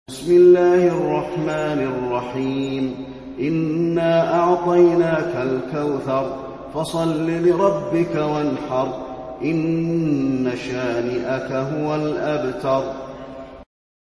المكان: المسجد النبوي الكوثر